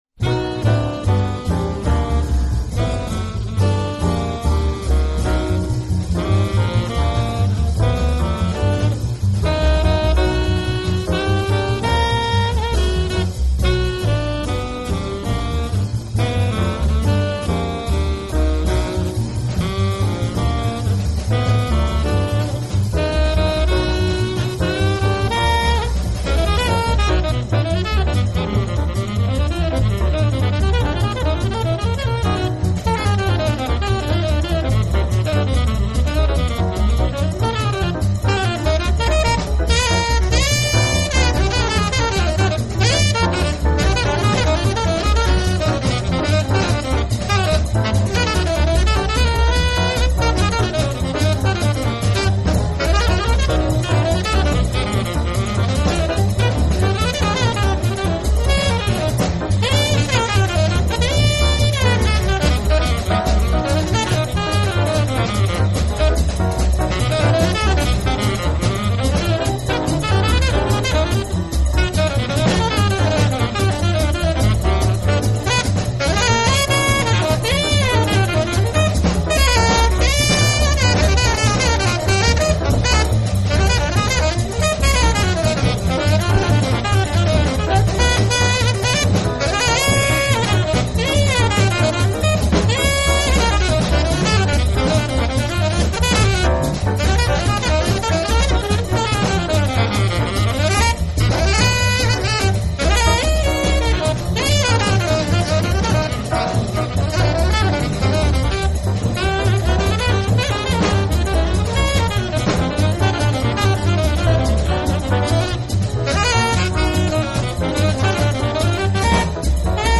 تغییرات هارمونیک پیچیده
پیانو
بیس
درام